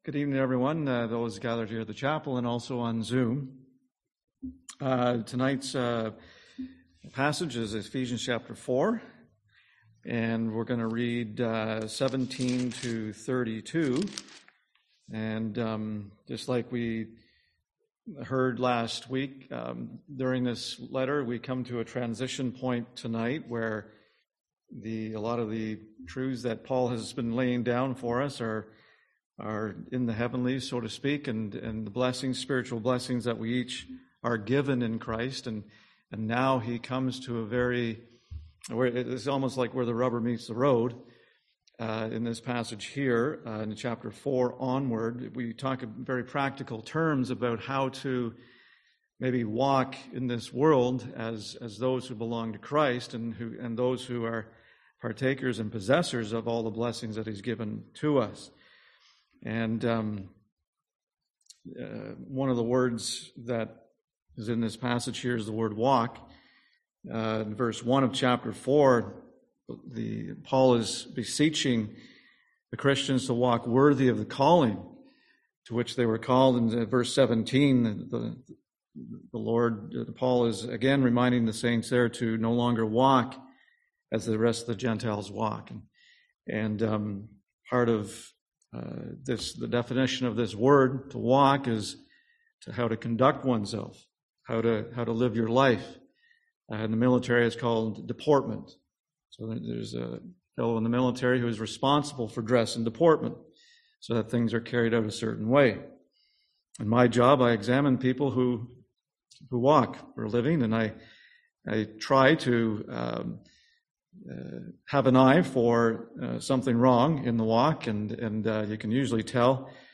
Series: Ephesians 2022 Passage: Ephesians 4:17-32 Service Type: Seminar